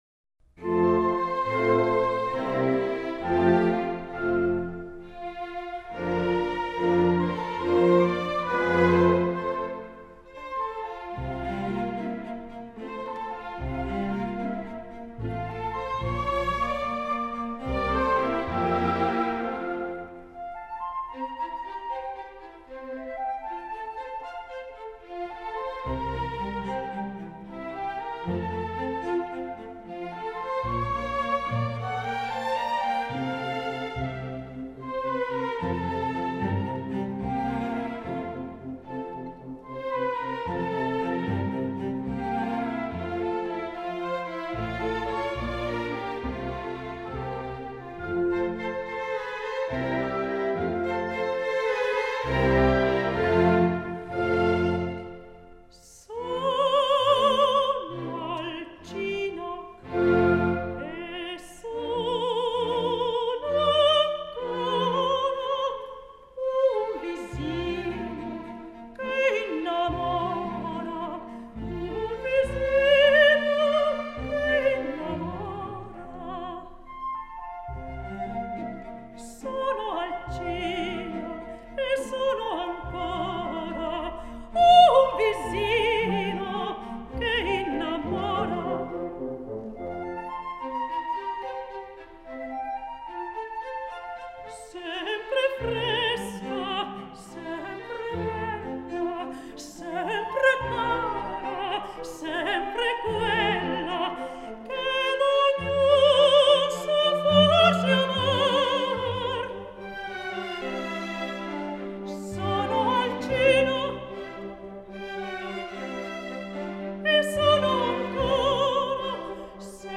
Надо же, какой чистый и красивый голос!